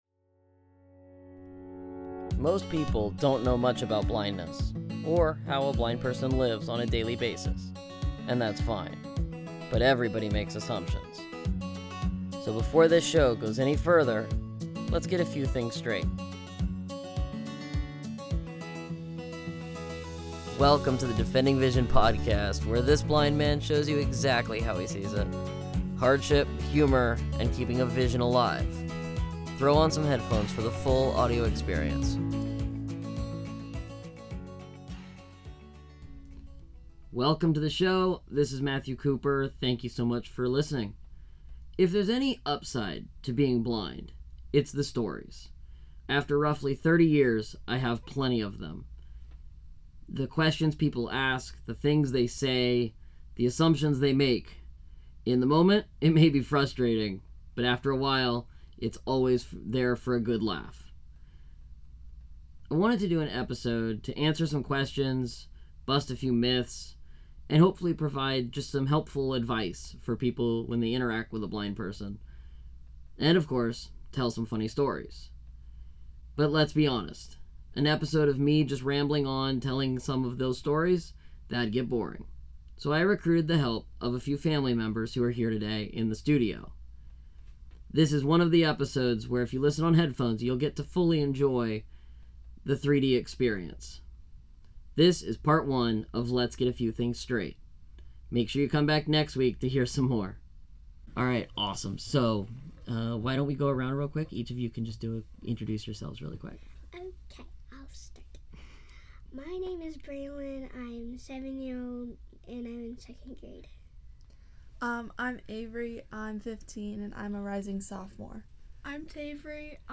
Listen, learn, and laugh along with the group in part 1 of this fun discussion.